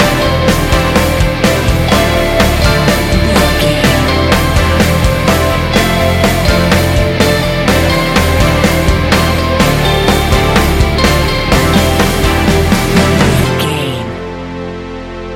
Ionian/Major
ambient
chill out
downtempo
pads